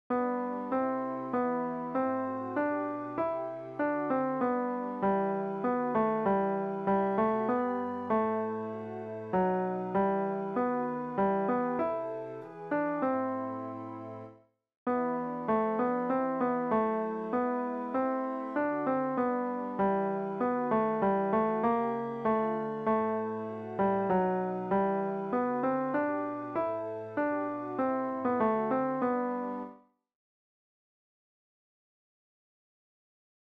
Chorproben MIDI-Files 510 midi files